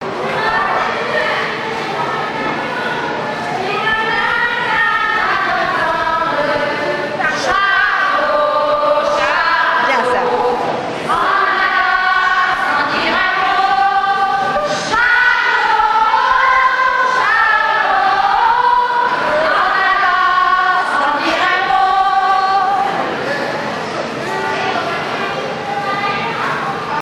Genre strophique
Enquête Mission Ile-d'Yeu
Chansons traditionnelles
Pièce musicale inédite